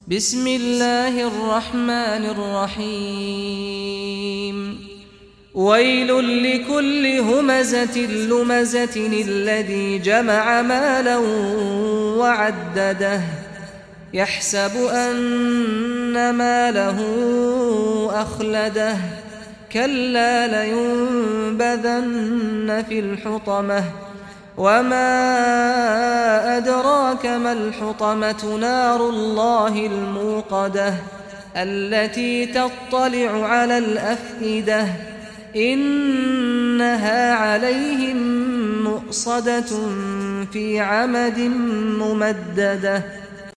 Surah Al-Humazah Recitation by Saad al Ghamdi
Surah Al-Humazah, listen or play online mp3 tilawat / recitation in Arabic in the beautiful voice of Sheikh Saad al Ghamdi.